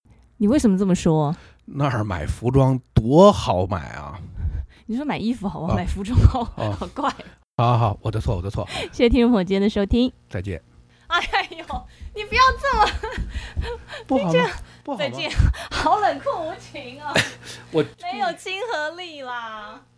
上面三段就是被美女主播无情删除的。